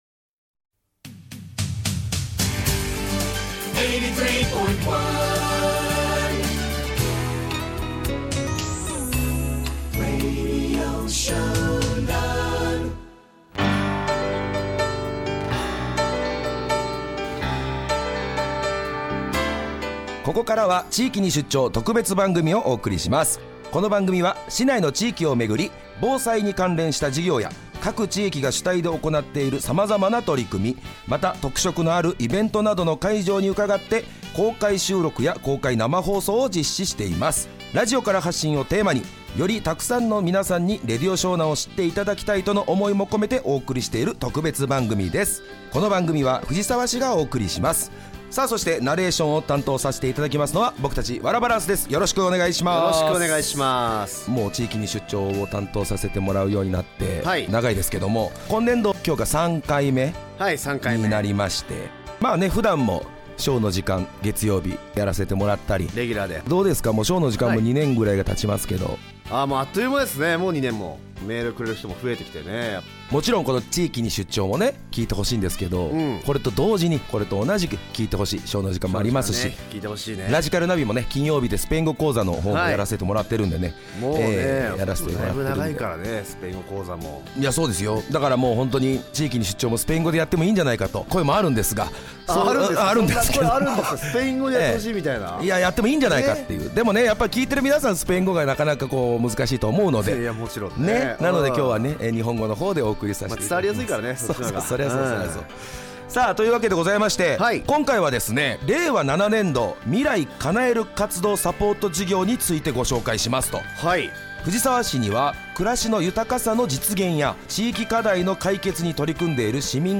令和7年度に市内の各地域で行われた特色のある取り組みやイベントに出張して、さまざまなテーマで公開収録し、放送された「地域に出張！」のアーカイブを音声にてご紹介いたします。